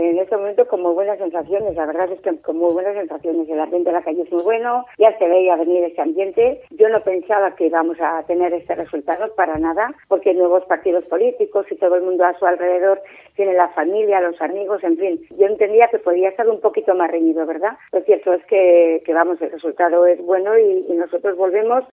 La alcaldesa electa de Alfaro ha pasado por los micrófonos de COPE para valorar esta victoria electorial.